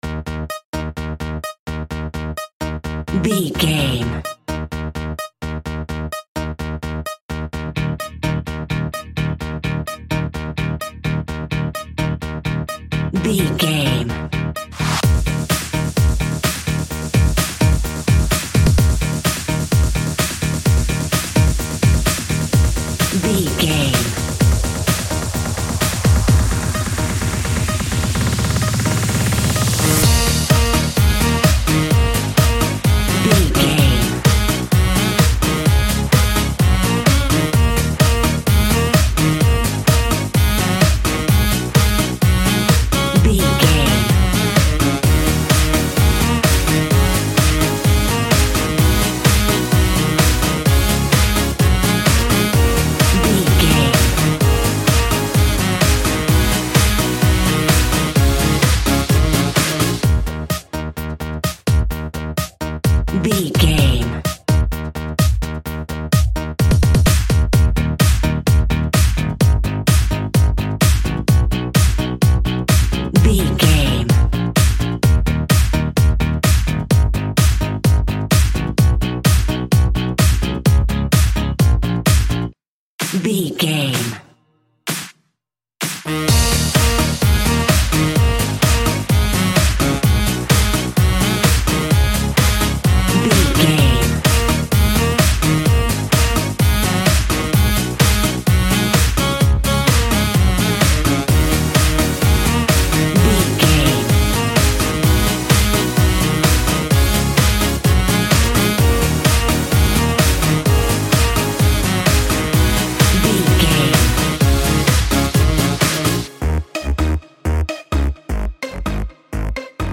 Ionian/Major
E♭
Fast
groovy
futuristic
hypnotic
uplifting
drum machine
synthesiser
house
electro dance
techno
synth leads
synth bass
upbeat